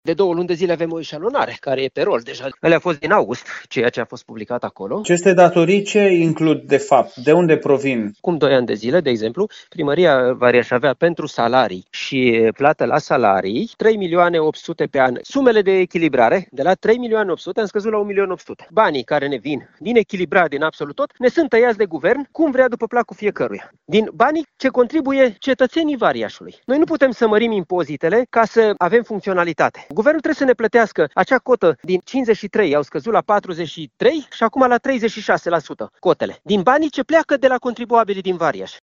Reacția primarului comunei
Primarul Nicolae Birău susține că instituția este deja în procedura de eșalonare a plăților.